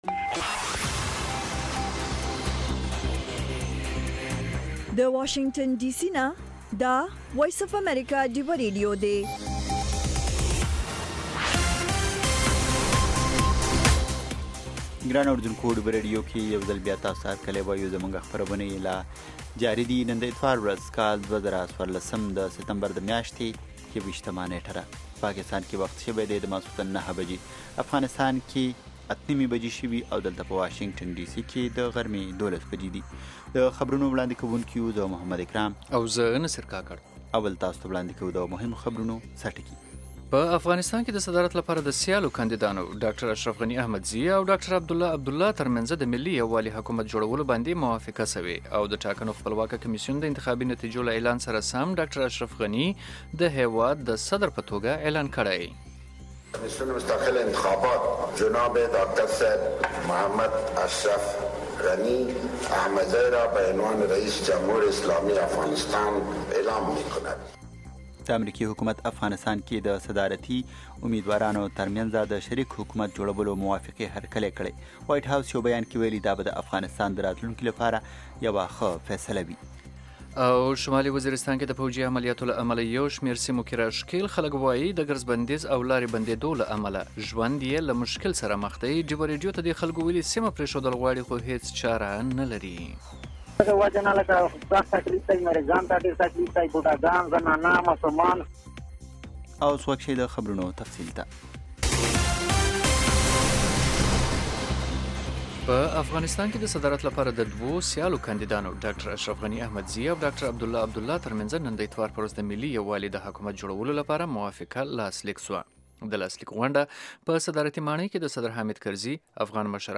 دا یو ساعته خپرونه خونده ورې سندرې لري میلمانه یې اکثره سندرغاړي، لیکوالان، شاعران او هنرمندان وي. مهال ويش هره ورځ